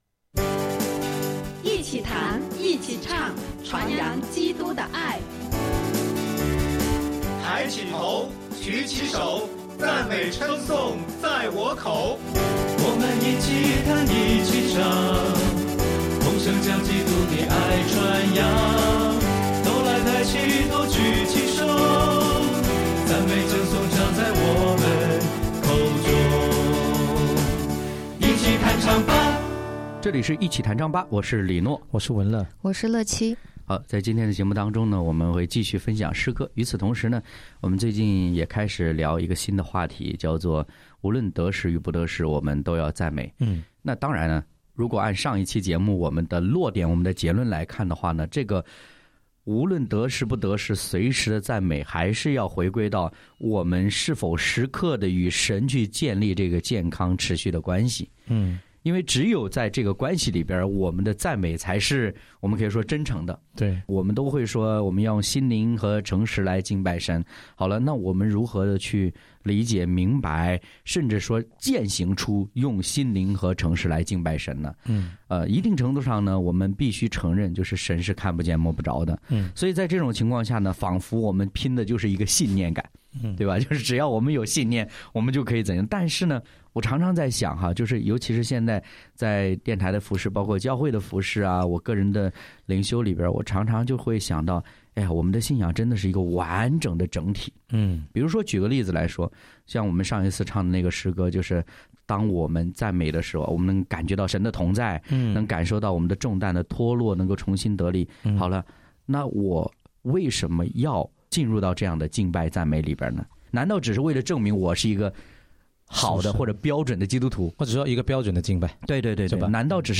敬拜分享：无论何时，总要赞美（2）；诗歌：《不停赞美》、《随时赞美》